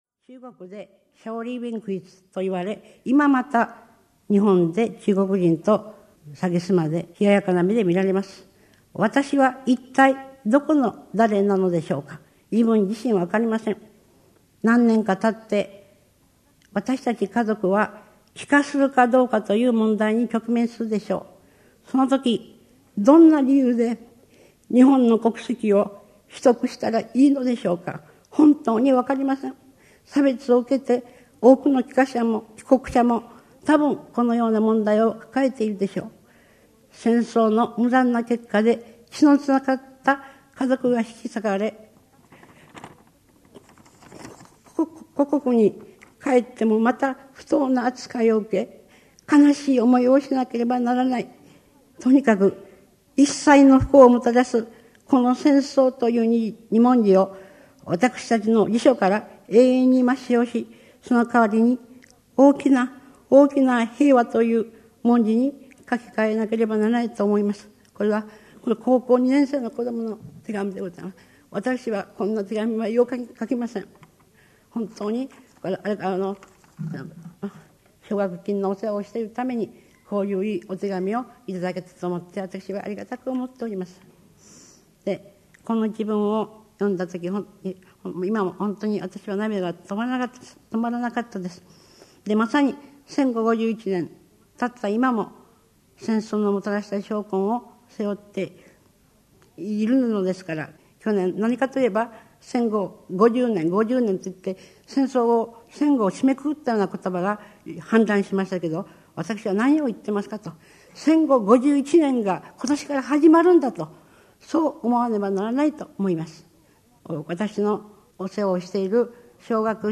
名だたる文筆家が登場する、文藝春秋の文化講演会。
（1996年4月4日 有楽町朝日ホール 文藝春秋文化講演会より）